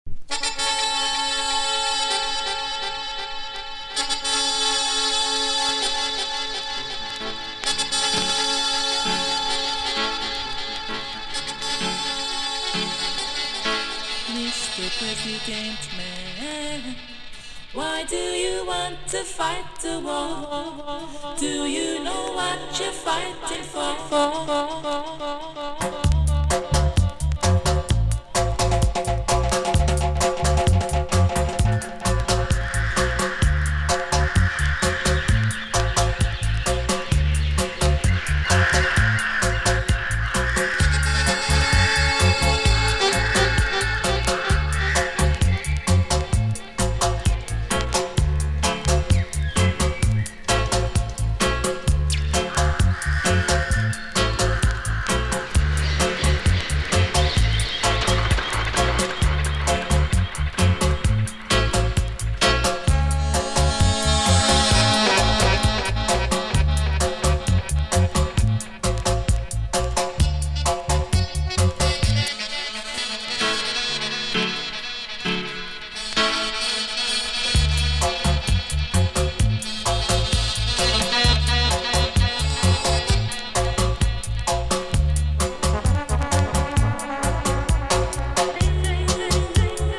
DUB / UK DUB / NEW ROOTS